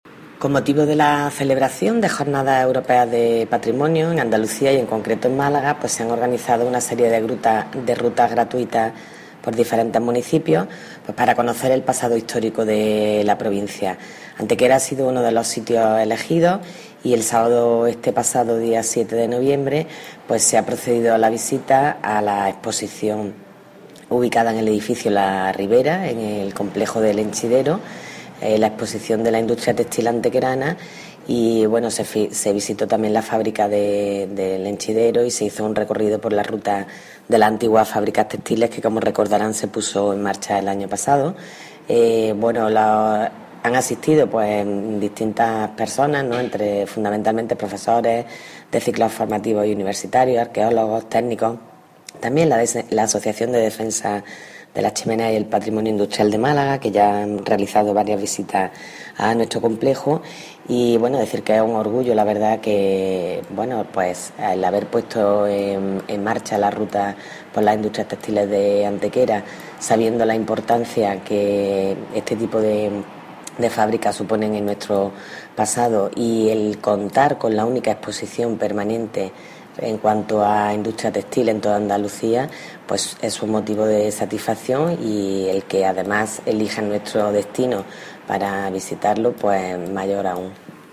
Generar Pdf viernes 13 de noviembre de 2015 La exposición permanente de la Industria Textil en Antequera, objeto de visita con motivo de las Jornadas Europeas de Patrimonio Generar Pdf La teniente de alcalde delegada de Turismo, Comercio y Promoción para el Empleo, Belén Jiménez, informa del desarrollado el pasado sábado día 7 de una visita enmarcada en la conmemoración que se hace en Andalucía con motivo de las Jornadas Europeas de Patrimonio y que se ha centrado en los vestigios industriales.
Cortes de voz B. Jiménez 712.26 kb Formato: mp3